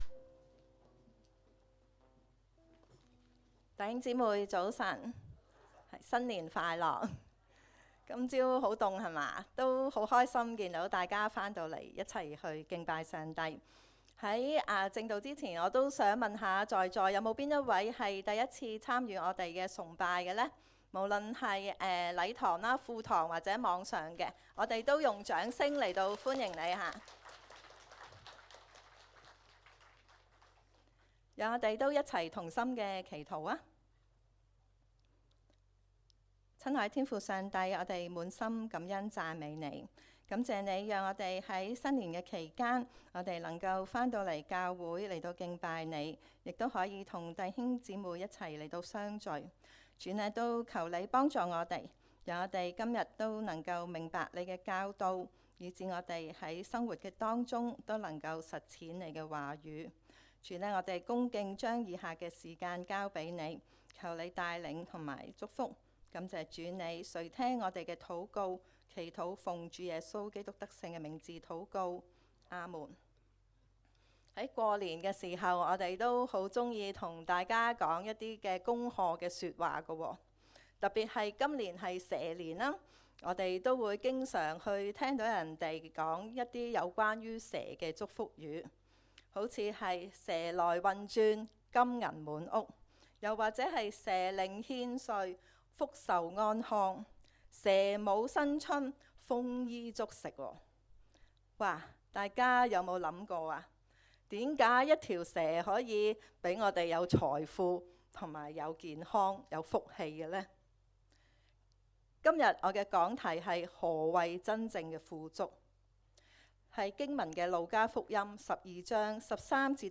證道重溫